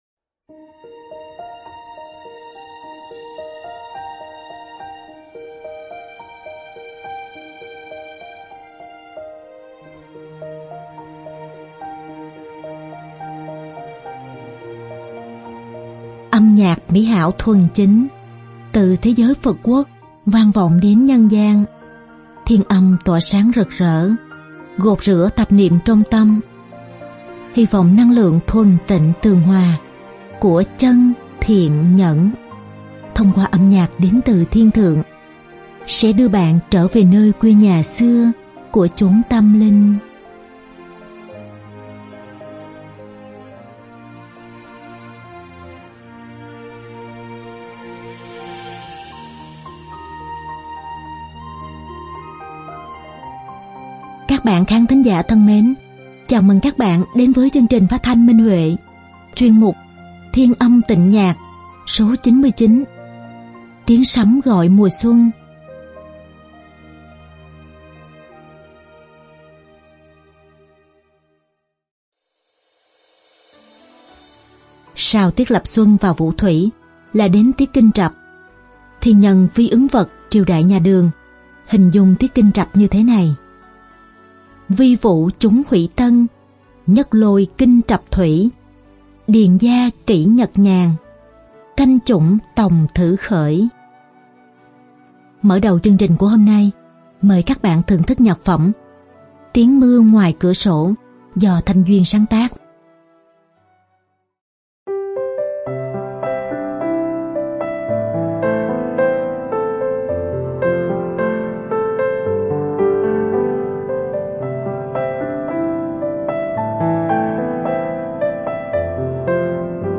Đơn ca nữ
Hợp xướng